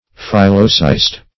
Search Result for " phyllocyst" : The Collaborative International Dictionary of English v.0.48: Phyllocyst \Phyl"lo*cyst\, n. [Phyllo- + cyst.]